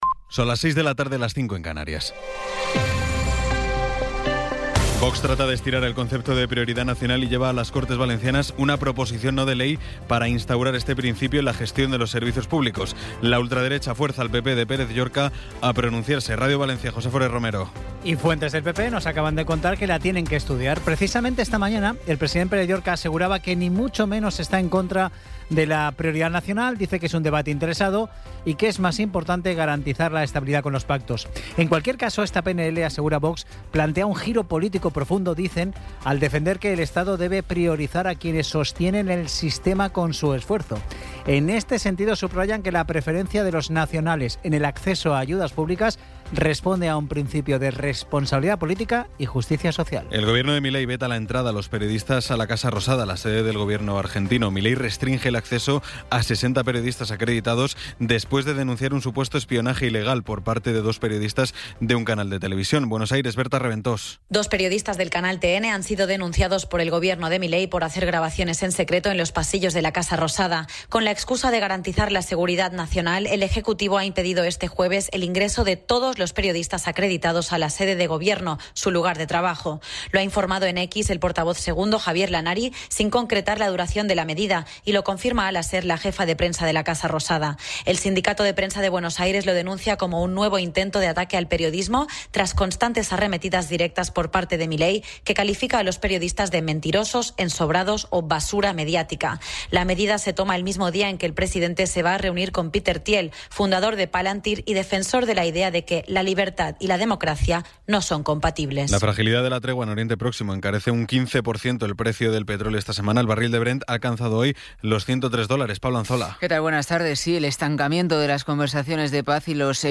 Resumen informativo con las noticias más destacadas del 23 de abril de 2026 a las seis de la tarde.